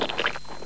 skwelch3.mp3